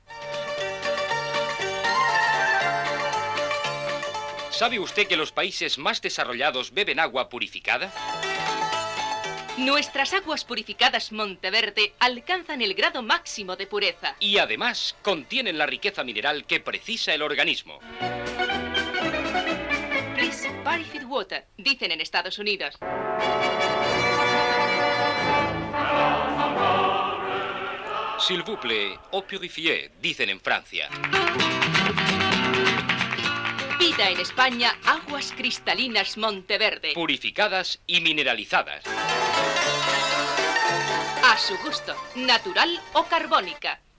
Dos anuncis de l'Agua Purificada Monte Verde